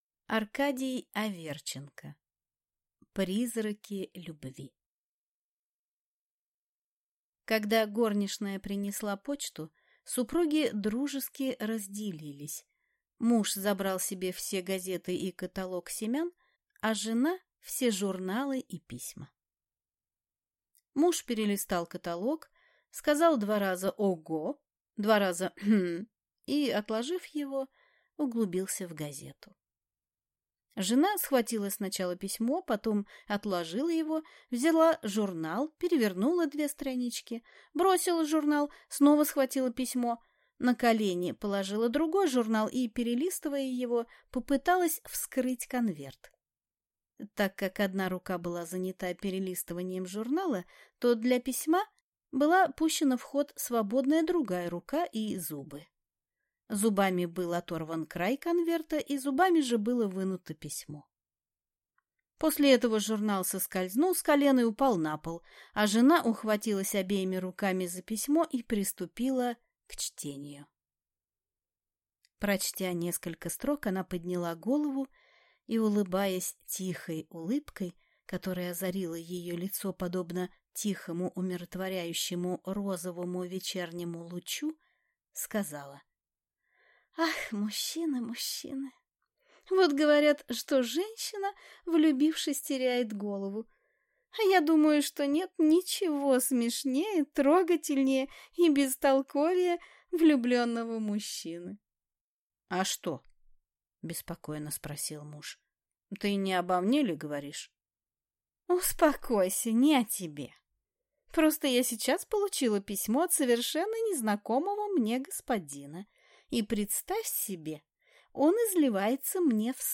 Аудиокнига Призраки любви | Библиотека аудиокниг